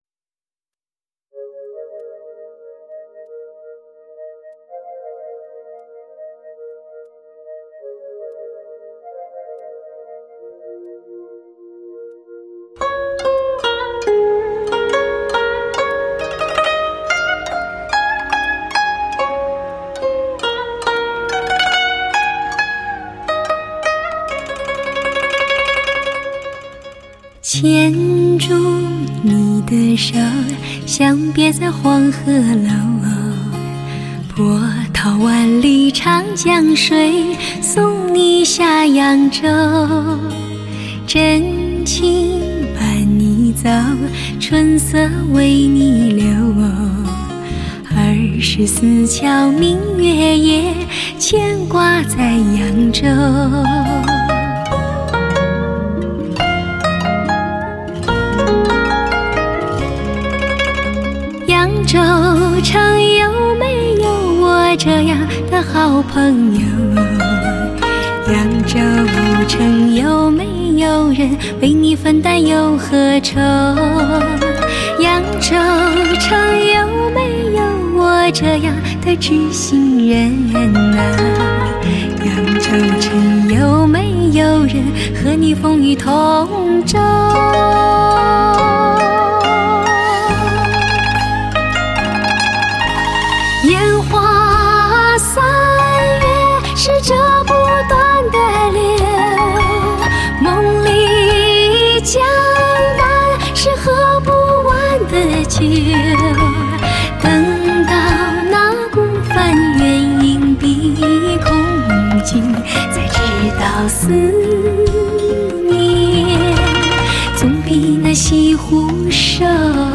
HI-FI顶级人生测试天碟
柔情似水的旋律，脍炙人口的情歌，娓娓动人的甜美歌声，让人魂梦绕……